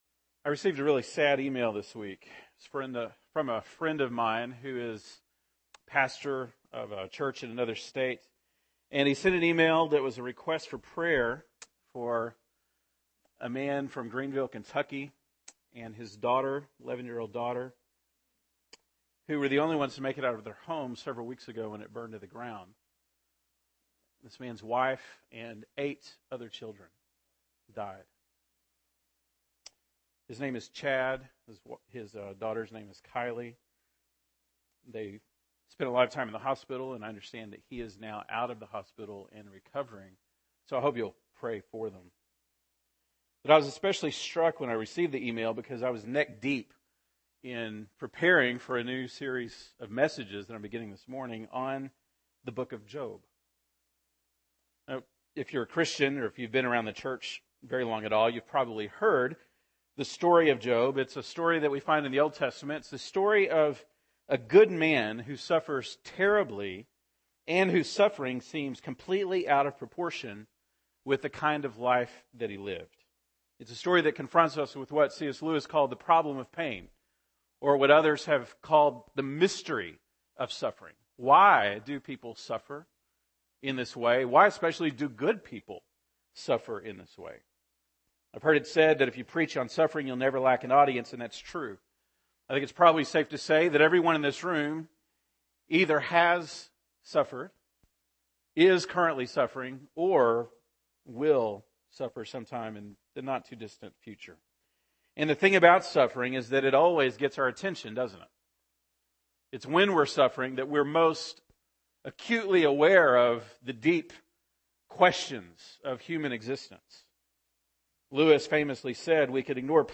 February 16, 2014 (Sunday Morning)